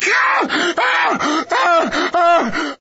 scream7.ogg